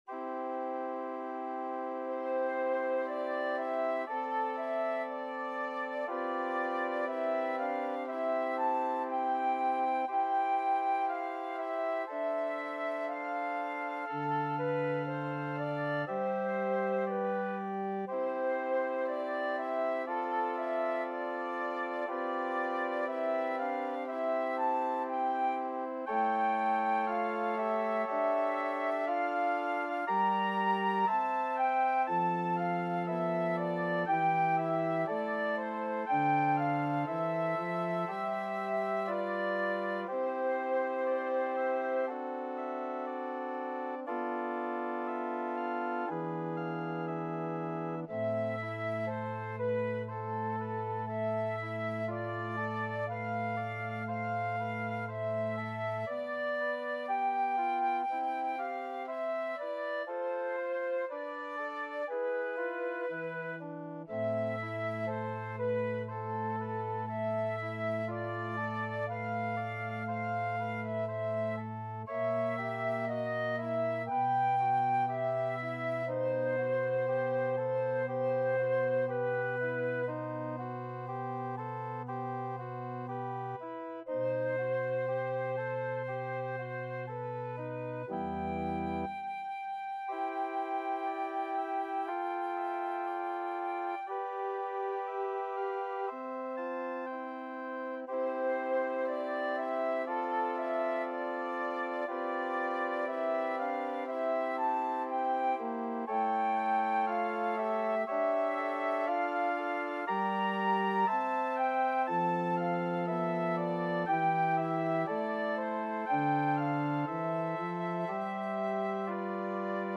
Flute version
2/4 (View more 2/4 Music)
Classical (View more Classical Flute Music)